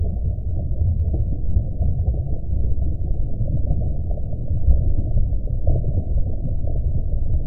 ambientsound_underwater.wav